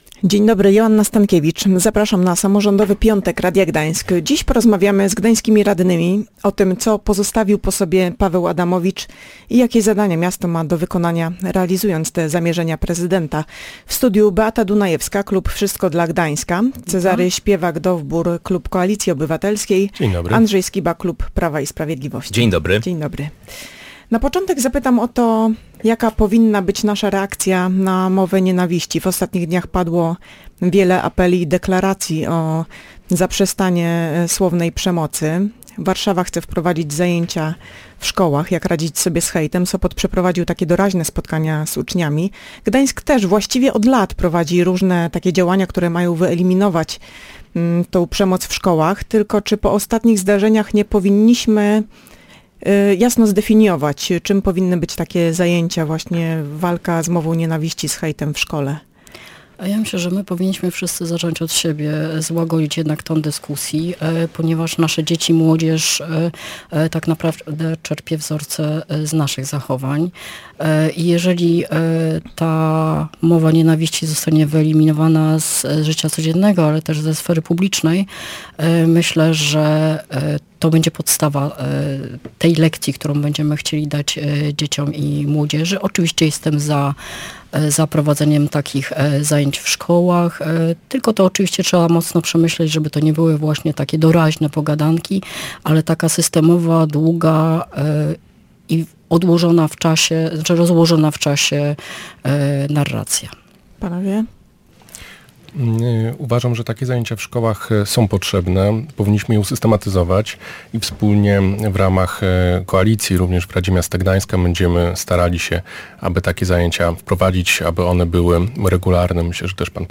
Gośćmi audycji Samorządowy Piątek byli gdańscy radni: Beata Dunajewska z klub Wszystko dla Gdańska, Cezary Śpiewak-Dowbór z Koalicji Obywatelskiej i Andrzej Skiba z PiS.